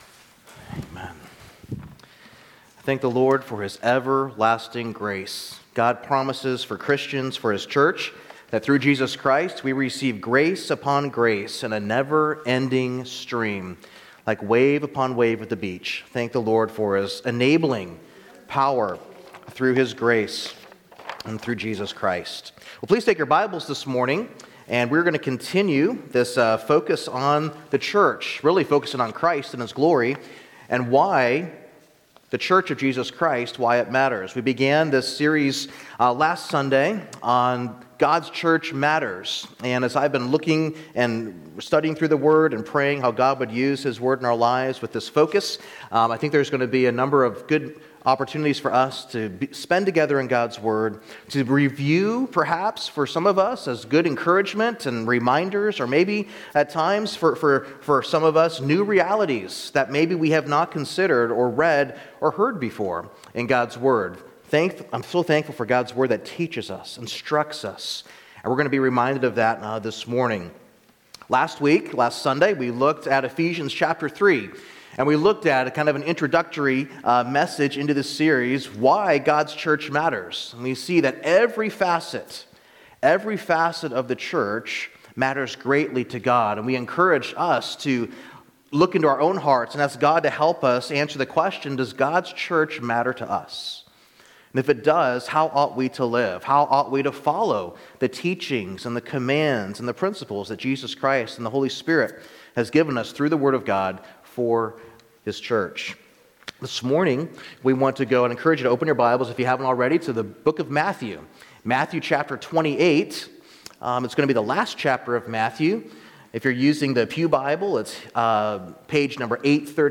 A message from the series "God\'s Church Matters."